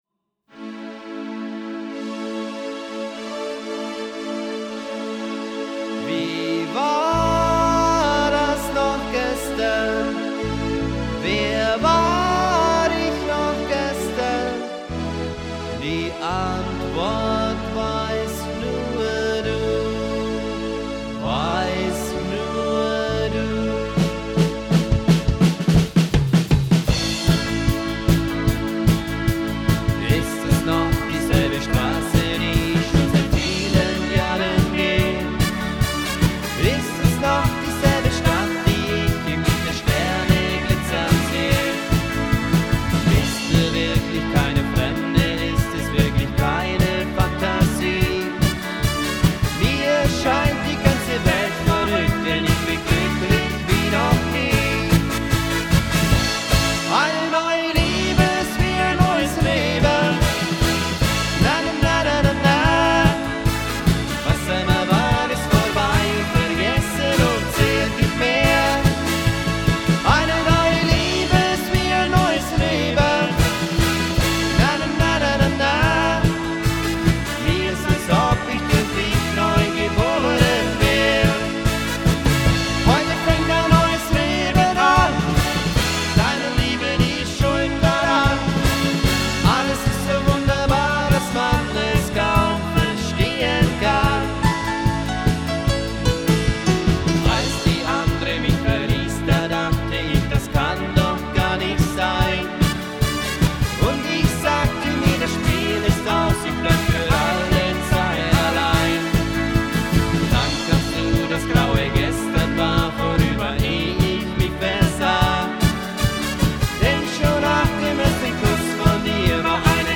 Ihre Hochzeitsband.